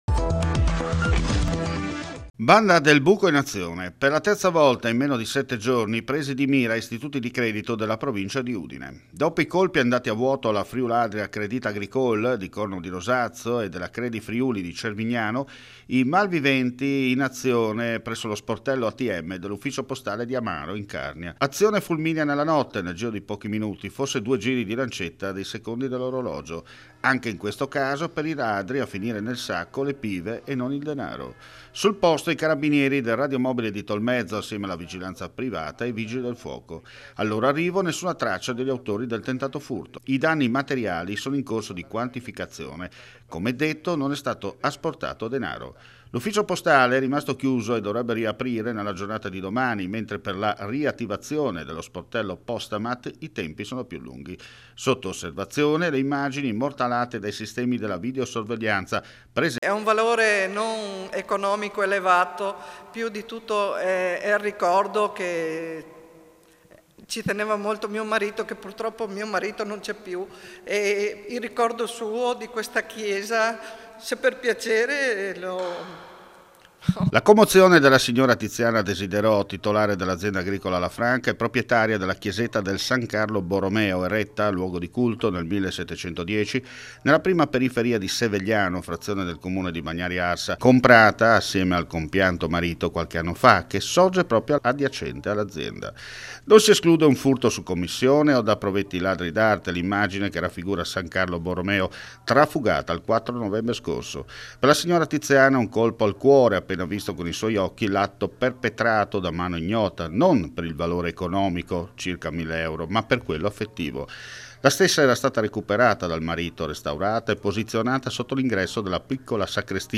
FRIULITV GIORNALE RADIO: LE AUDIONOTIZIE DAL FRIULI VENEZIA GIULIA